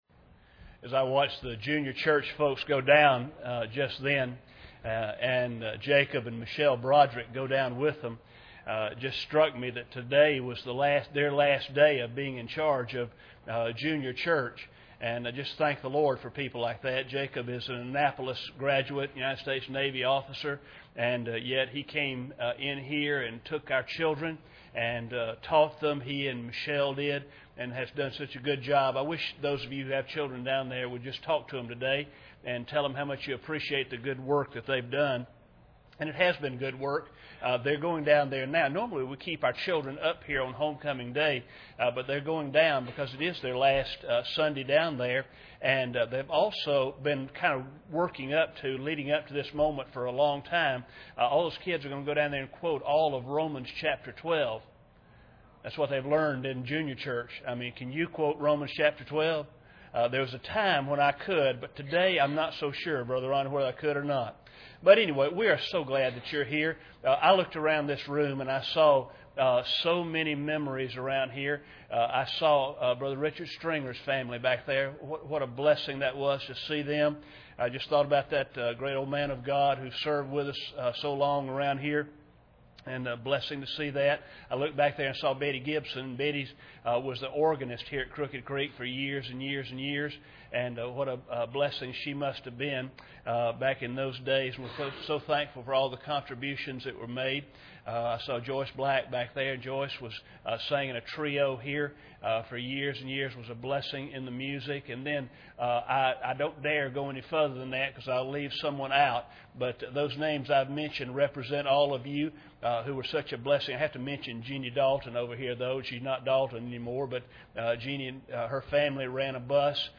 1 Corinthians 15:58 Service Type: Sunday Morning Bible Text